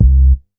MoogDog 005.WAV